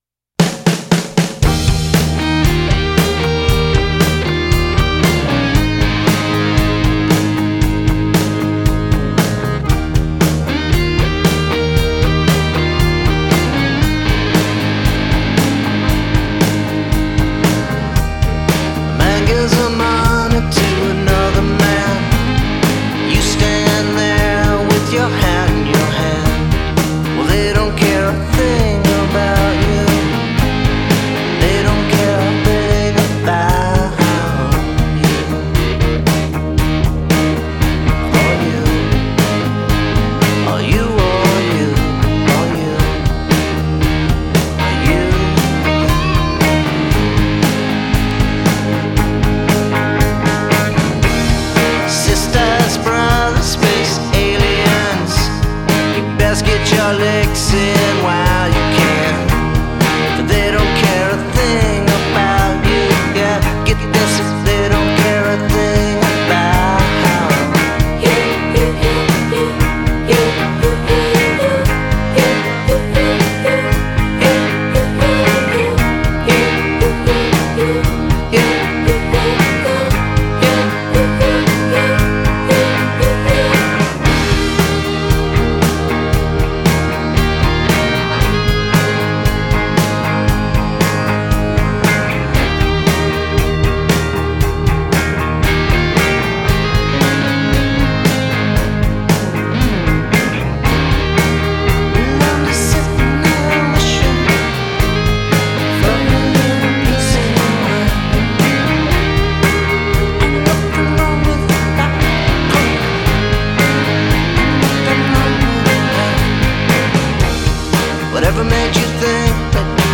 Indie rock, southern gothic, a little krautrock and a punk